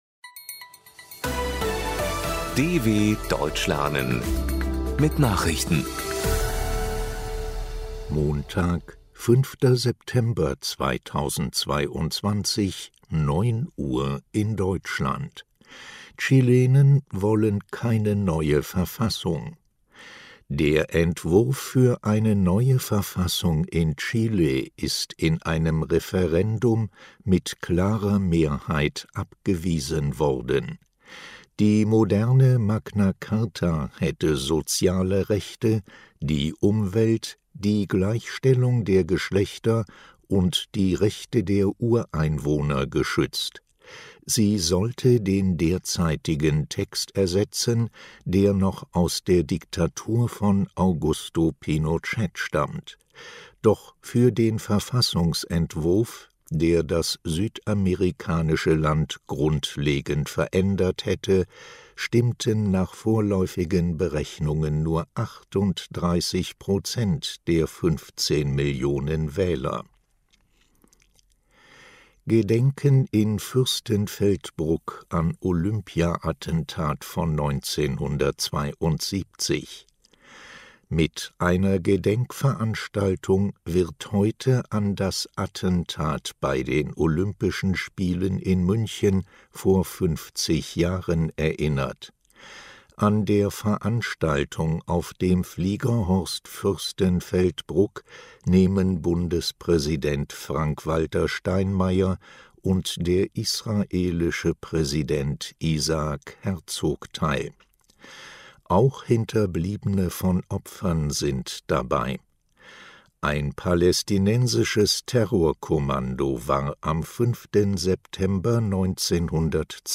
05.09.2022 – Langsam gesprochene Nachrichten
Trainiere dein Hörverstehen mit den Nachrichten der Deutschen Welle von Montag – als Text und als verständlich gesprochene Audio-Datei.